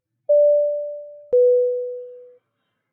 beep
alarm beep chime sound effect free sound royalty free Sound Effects